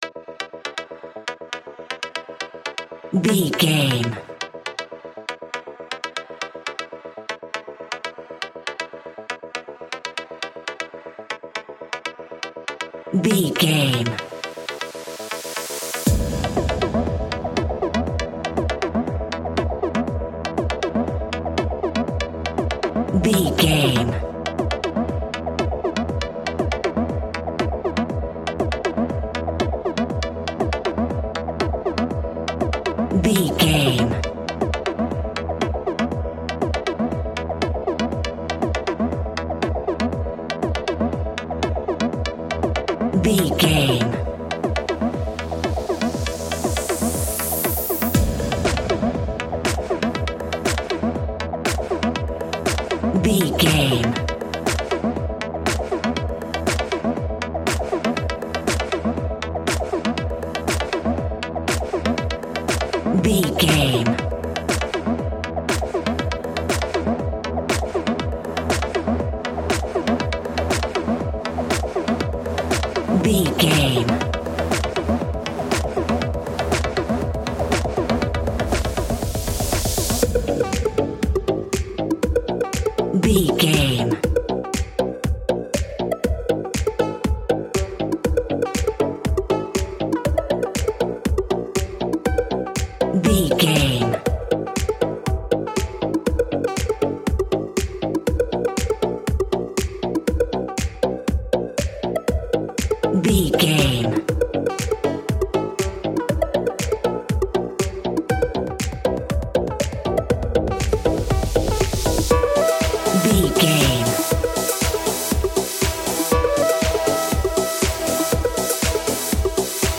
Aeolian/Minor
groovy
dreamy
smooth
futuristic
driving
drum machine
synthesiser
house
techno
trance
instrumentals
synth leads
synth bass
upbeat